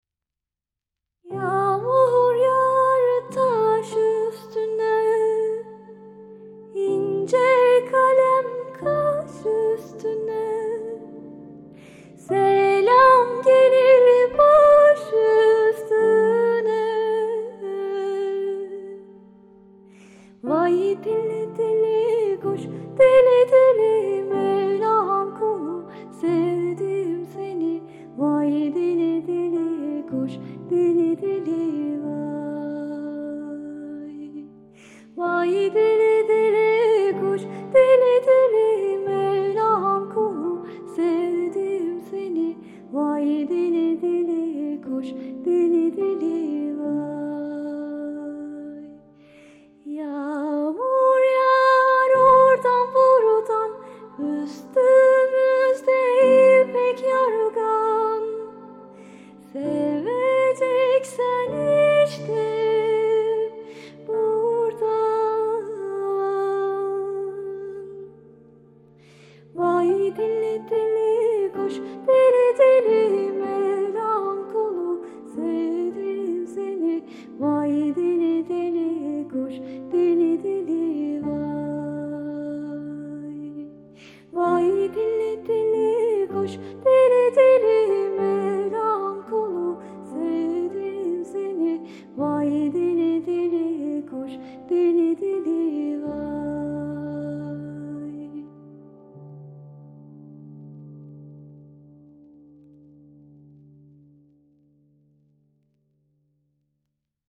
duygusal hüzünlü rahatlatıcı şarkı.